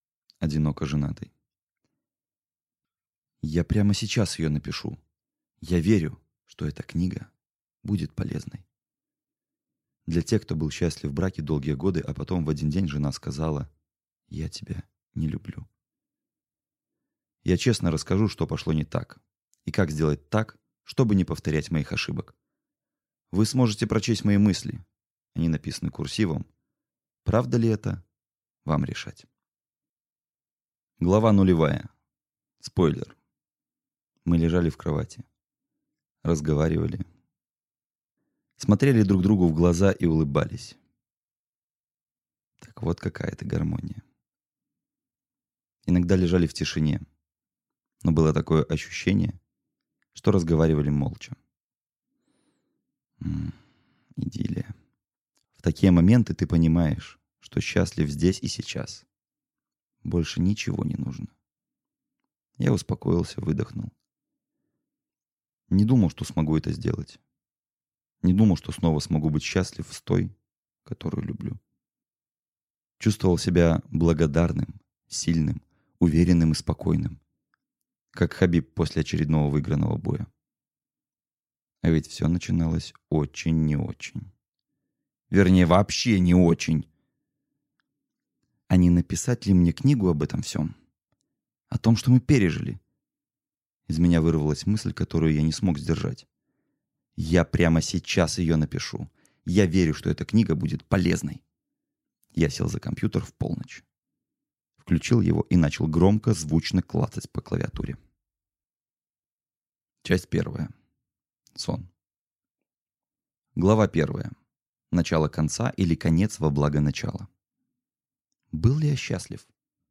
Аудиокнига Одиноко женатый | Библиотека аудиокниг